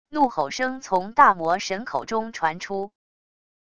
怒吼声从大魔神口中传出wav音频